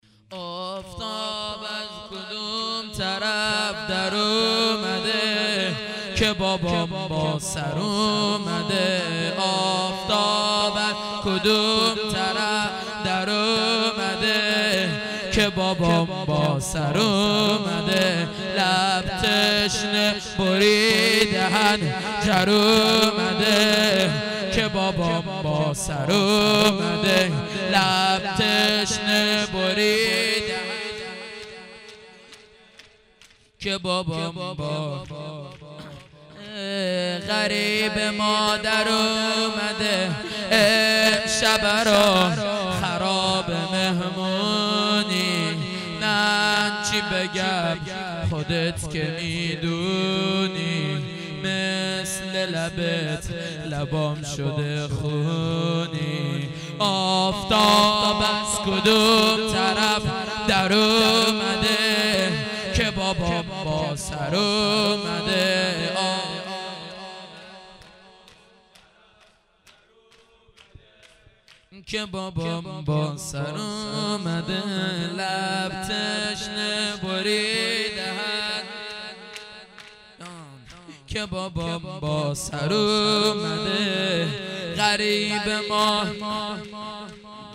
heiat-levaolabbasshab-shahadat-hazrat-roghaieh-shor.mp3